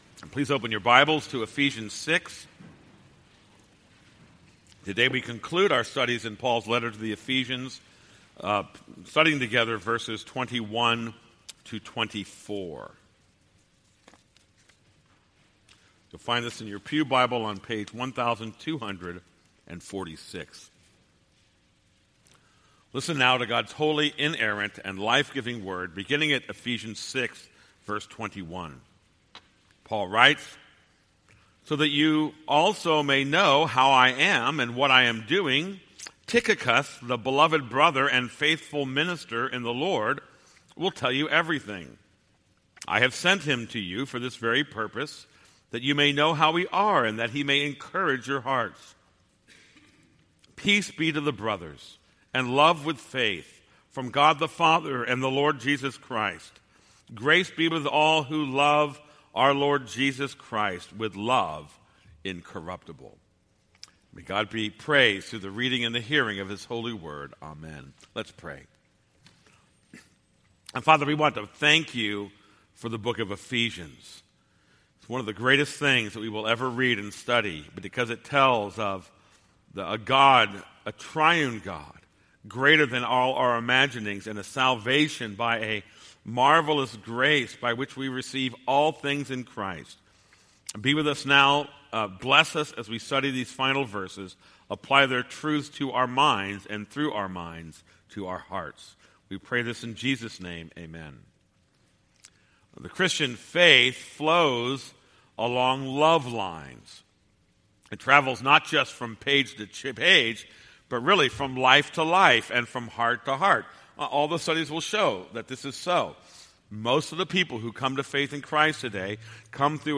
This is a sermon on Ephesians 6:21-24.